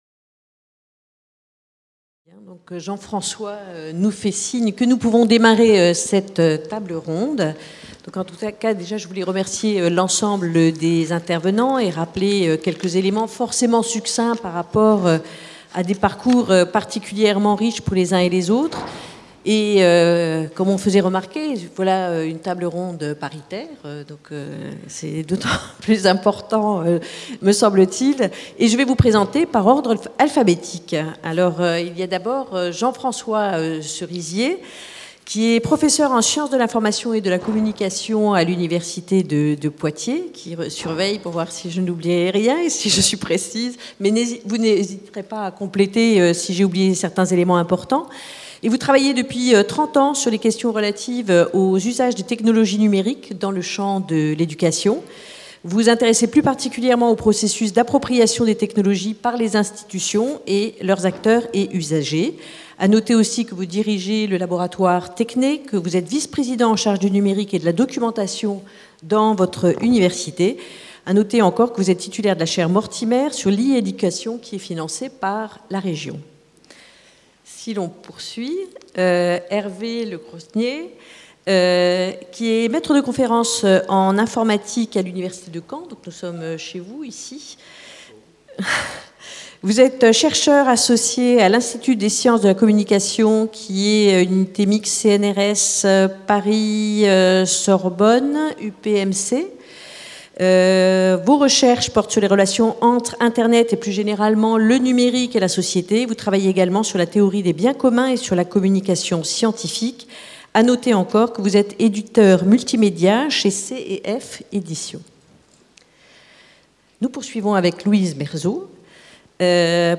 Colloque de La Conférence des présidents d’université (CPU) Université de Caen Normandie 30 novembre 2016
Table ronde n°3